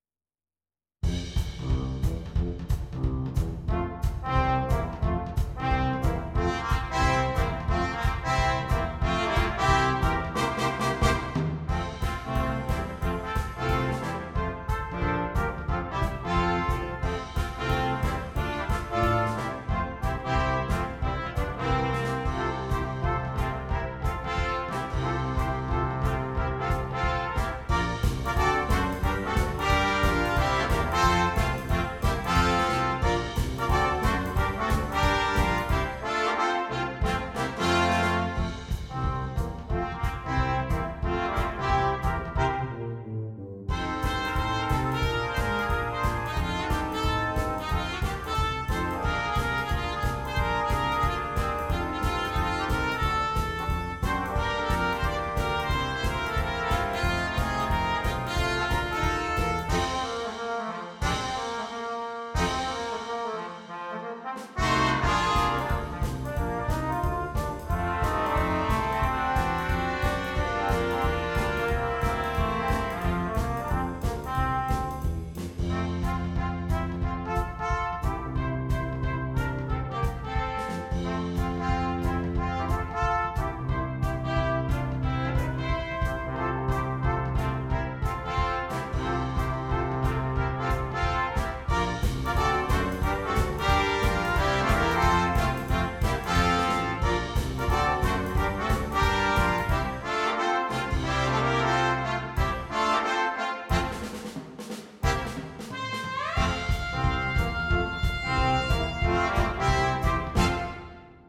Christmas
Brass Quintet (optional Drum Set)
This jump swing version
will definitely get the toes of listeners tapping.